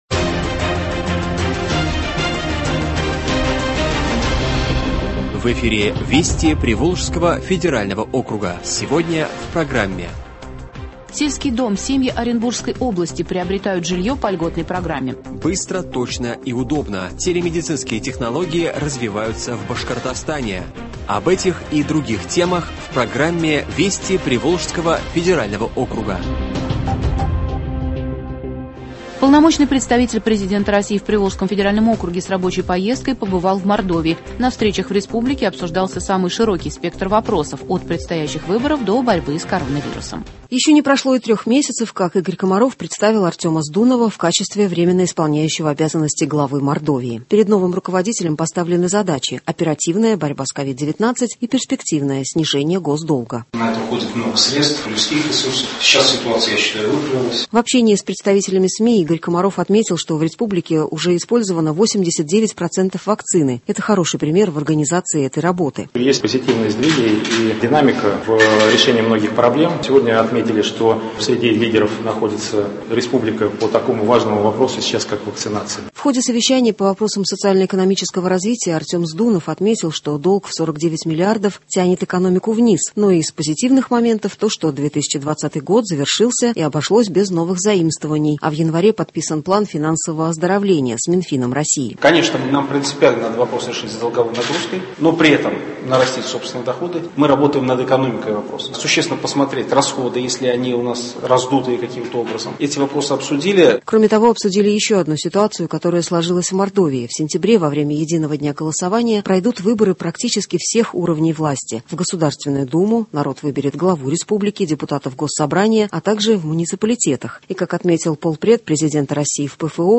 Радиообзор событий недели в регионах ПФО.